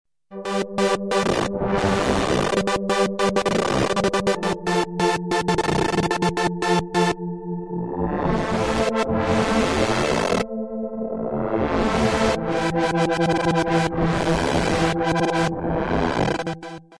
Elka Synthex
shape: triangle /saw/ ramp / square
Insane LFO